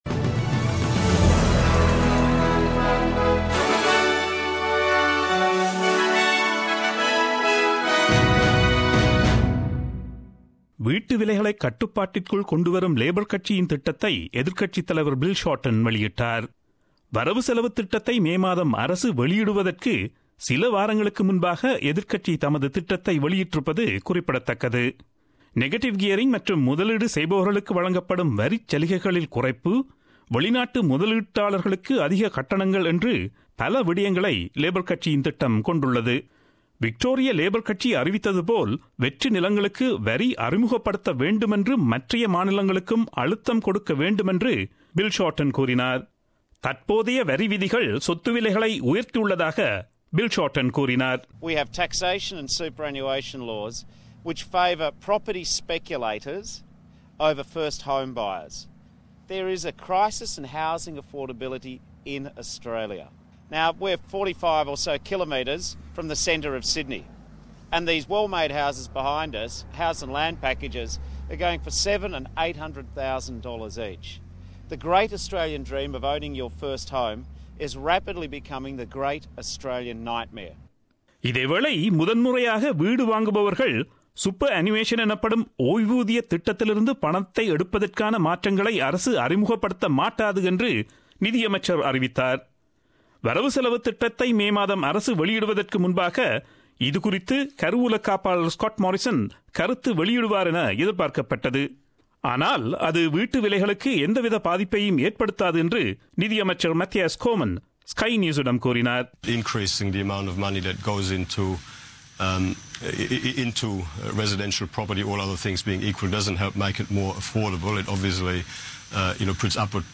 Australian news bulletin aired on Friday 21 Apr 2017 at 8pm.